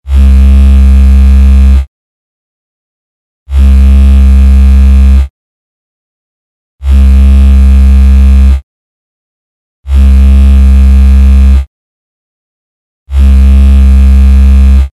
Для нас это любое жужжание которое возникает при быстрой работе любого прибора или частых ударах по нему.
Звук вибрации:
zvuk-vibracii.mp3